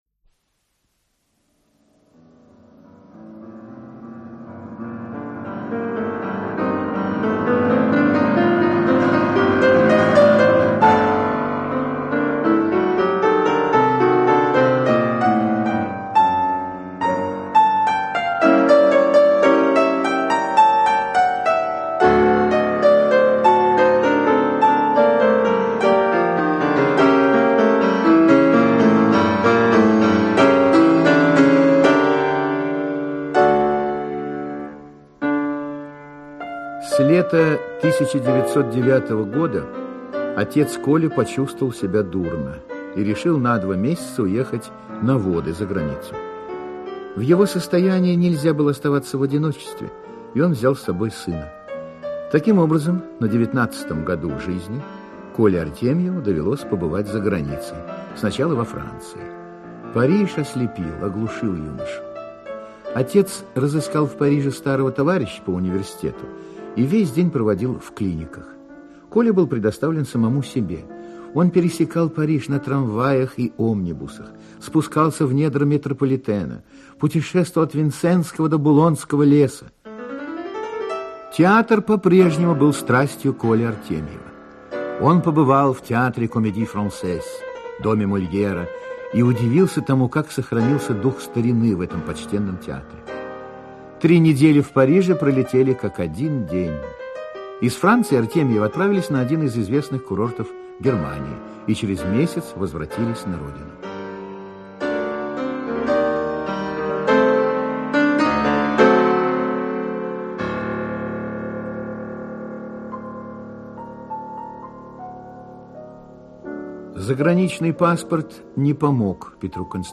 Аудиокнига Московские зори. Часть 1 | Библиотека аудиокниг
Часть 1 Автор Лев Вениаминович Никулин Читает аудиокнигу Актерский коллектив.